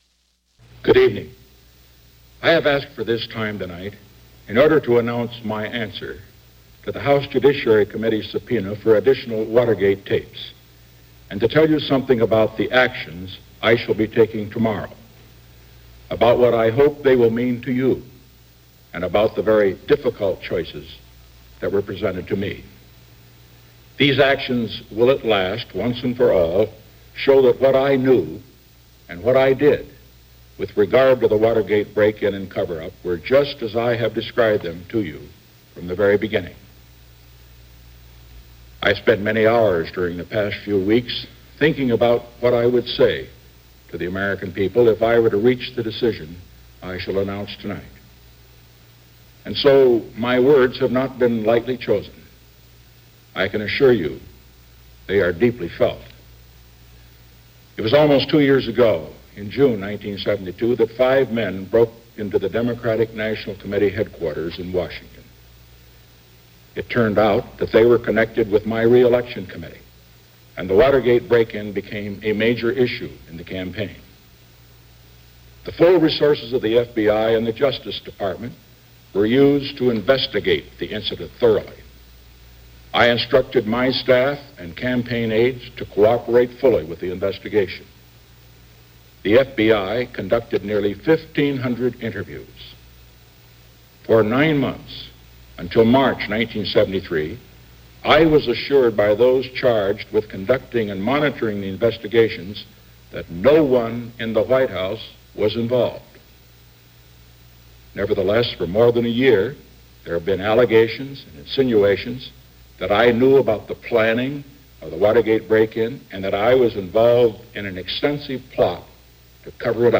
So on the night of April 29, 1974 – Nixon took to the airwaves to deliver an address in which it was hoped questions were answered and reasons given: